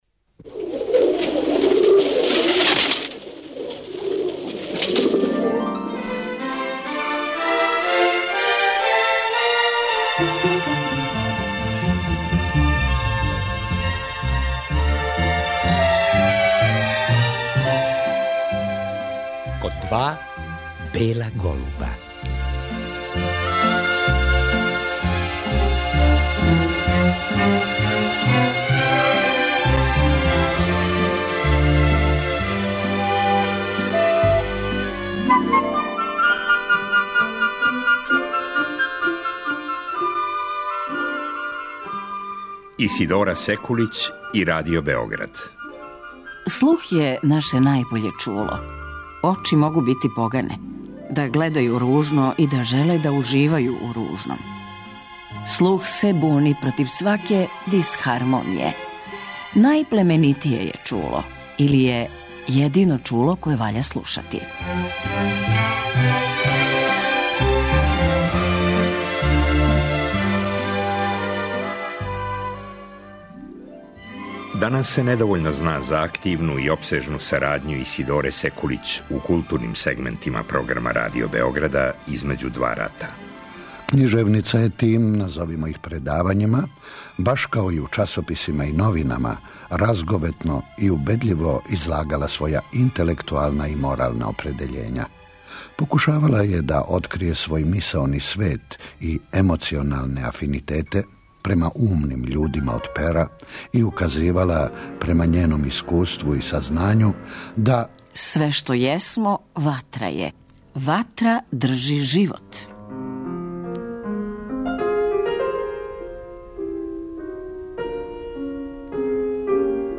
А чућемо и глас саме књижевнице.